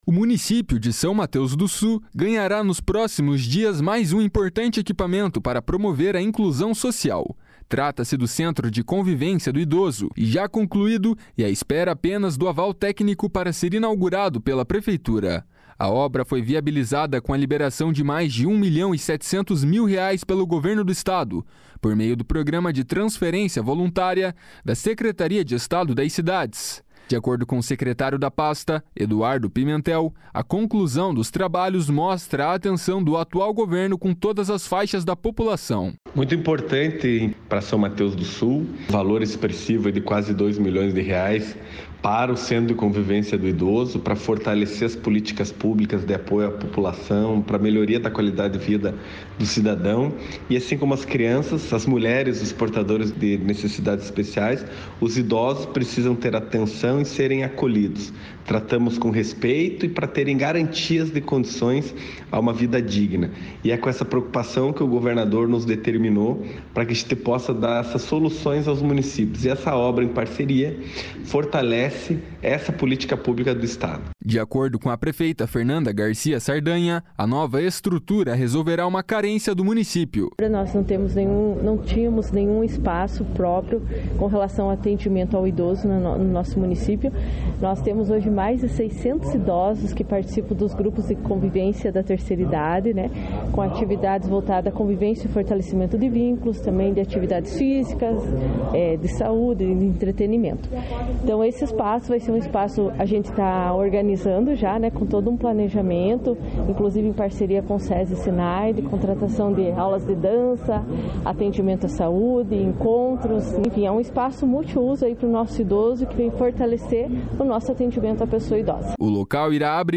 // SONORA FERNANDA GARCIA //